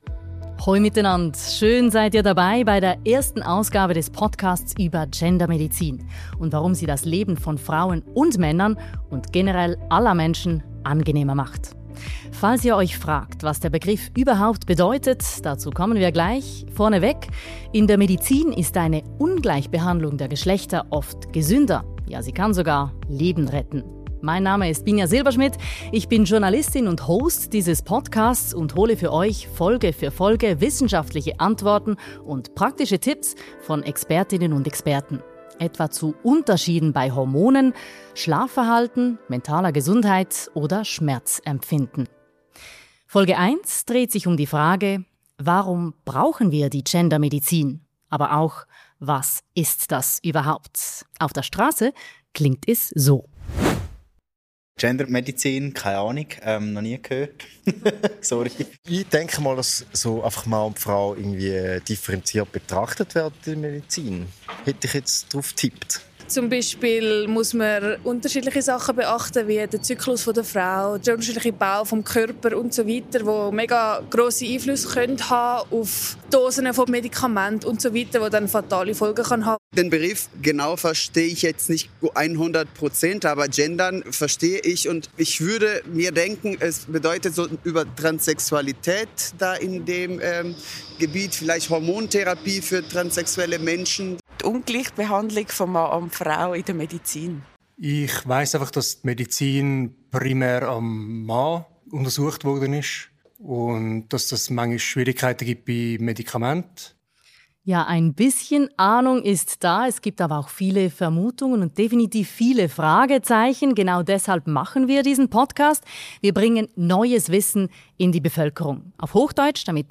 Ein Gespräch über hartnäckige Wissenslücken, historische Weichenstellungen – und darüber, warum mehr Gendermedizin nicht nur Frauen, sondern generell allen Menschen nützt.